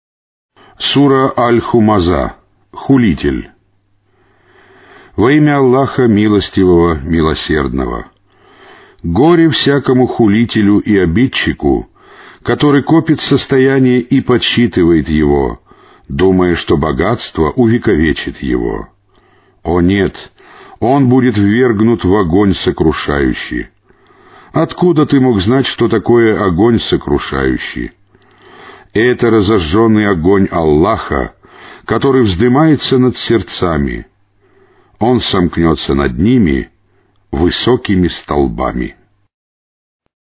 Каналы 1 (Mono).
Аудиокнига: Священный Коран